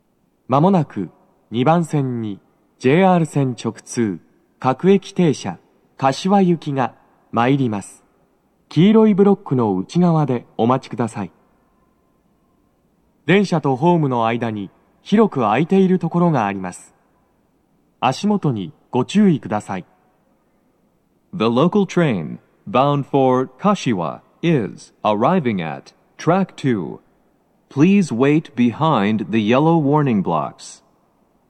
鳴動は、やや遅めです。鳴動中に入線してくる場合もあります。
接近放送2